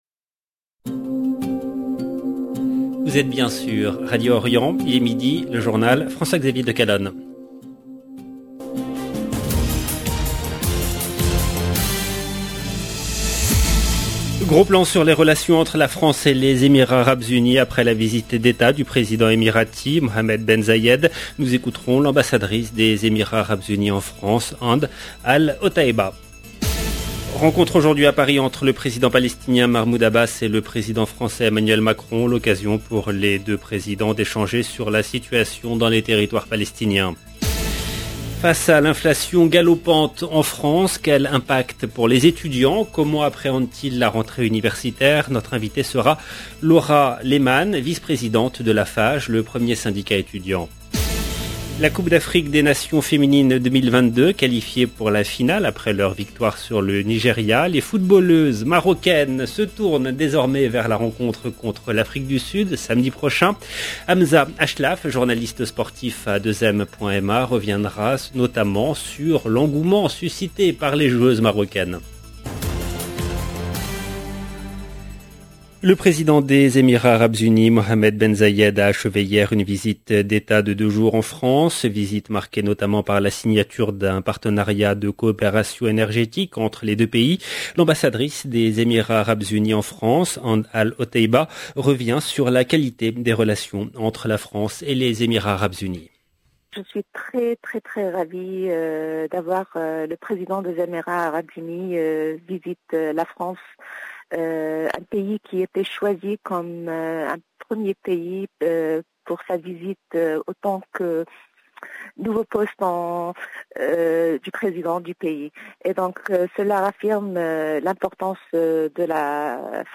Gros plan sur les relations entre la France et les Emirats arabes unis après la visite d’Etat du président émirati Mohamed ben Zayed. Nous écouterons l'ambassadrice des Émirats arabes unis en France, Hend al-Otaiba. Rencontre aujourd’hui à Paris entre le président palestien Mahmoud Abbas et le président français Emmanuel Macron.